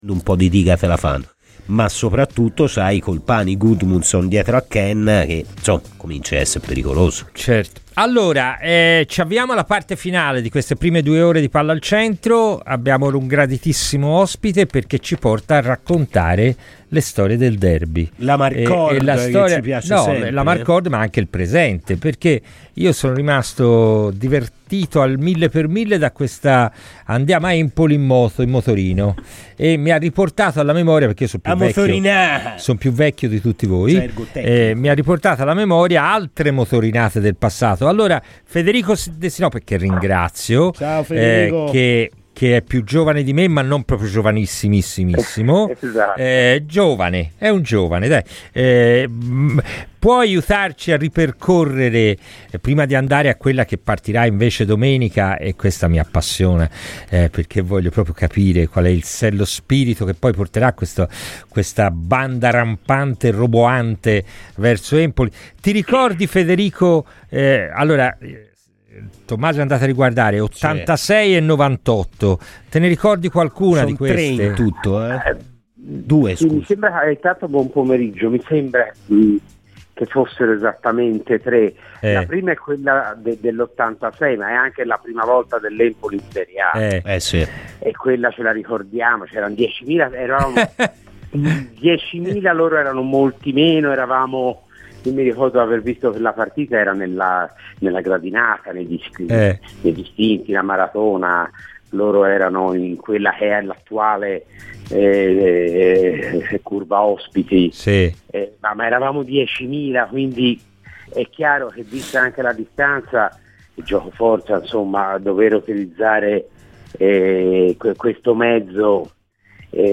ASCOLTA IL PODCAST DELL'INTERVISTA COMPLETA